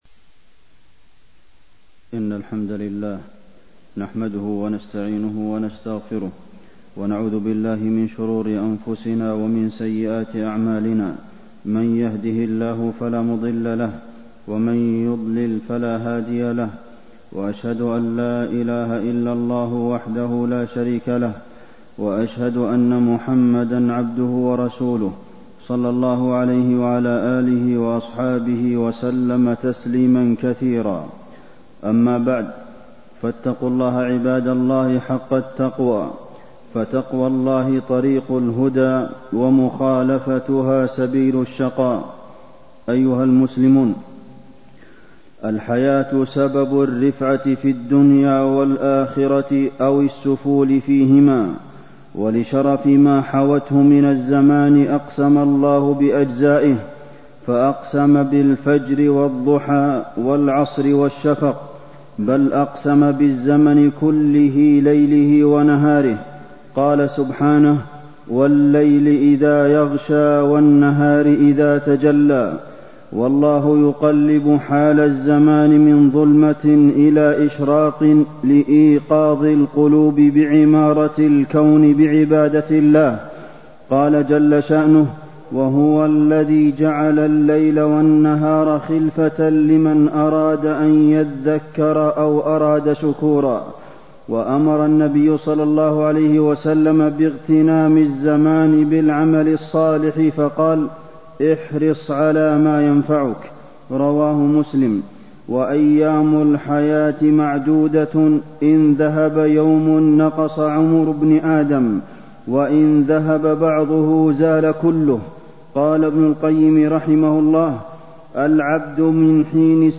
تاريخ النشر ٢٥ رجب ١٤٣٣ هـ المكان: المسجد النبوي الشيخ: فضيلة الشيخ د. عبدالمحسن بن محمد القاسم فضيلة الشيخ د. عبدالمحسن بن محمد القاسم اغتنام لحظات العمر